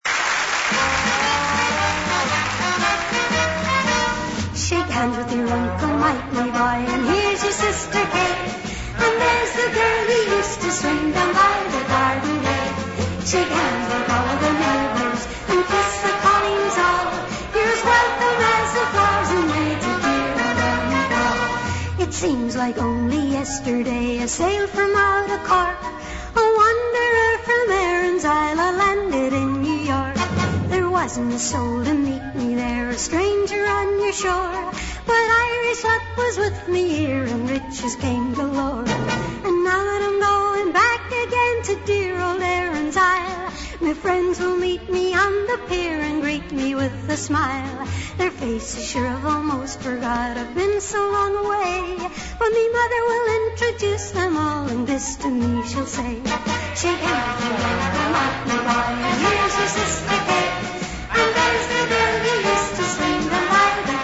live, vinyl